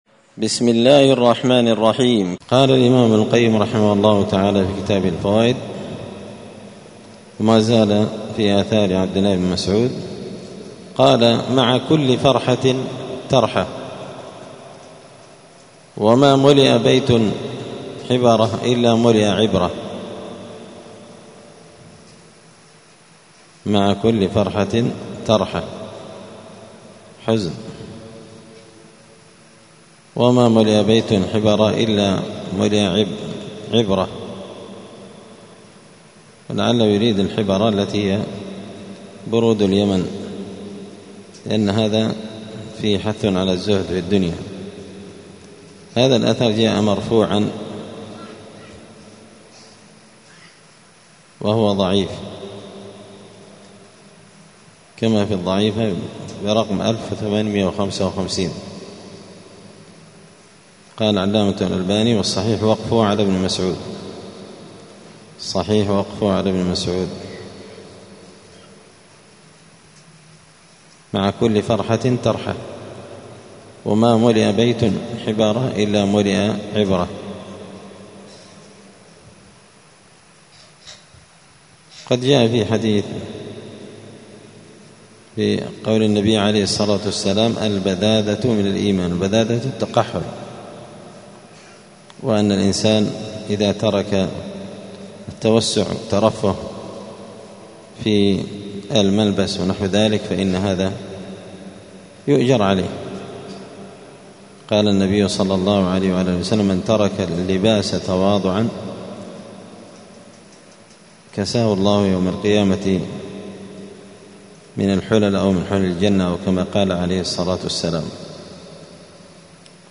*الدرس الثالث والثمانون (83) {فصل من قول ابن مسعود : ﻣﻊ ﻛﻞ ﻓﺮﺣﺔ ﺗﺮﺣﺔ}*